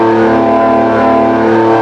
rr3-assets/files/.depot/audio/Vehicles/v6_f1/f1_v6_low.wav
f1_v6_low.wav